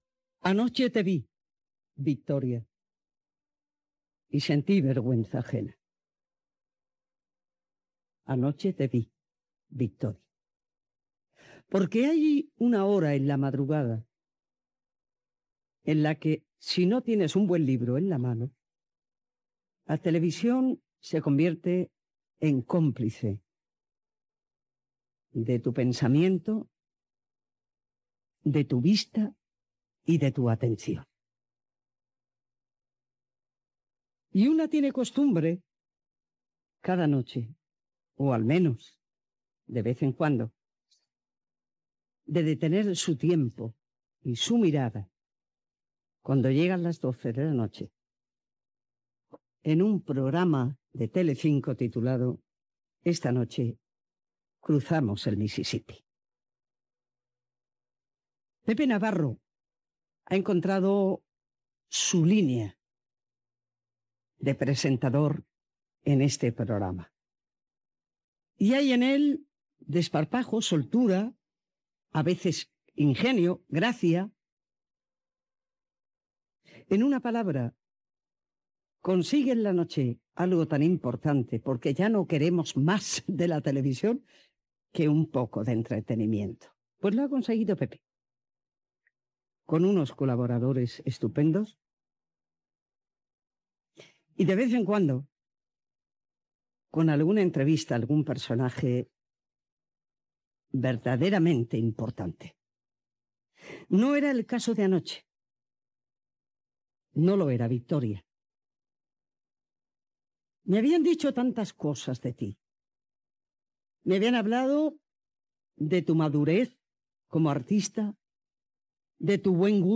Últim editorial que Encarna Sánchez va fer al seu programa, dedicat a "Esta noche cruzamos el Mississippi" de Telecinco i a la interveció de l'actriu Victoria Abril